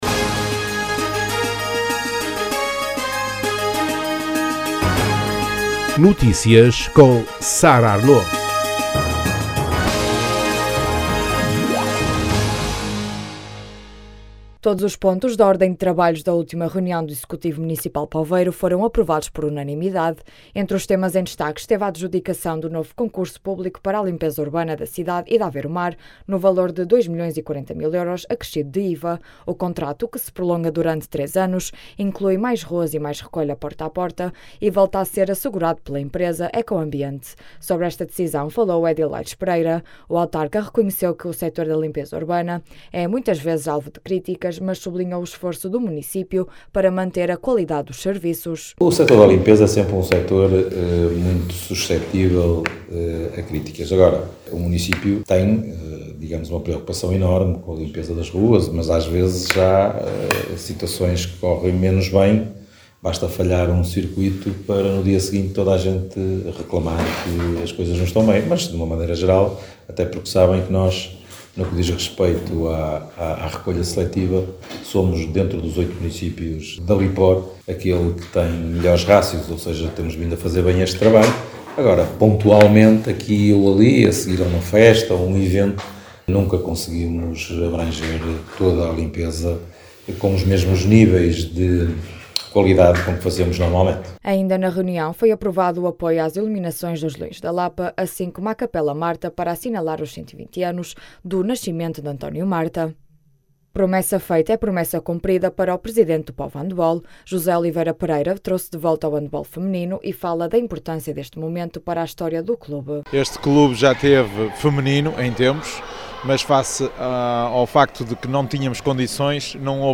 Sobre esta decisão, falou o edil Aires Pereira. O autarca reconheceu que o setor da limpeza urbana é muitas vezes alvo de críticas, mas sublinhou o esforço do município para manter a qualidade dos serviços.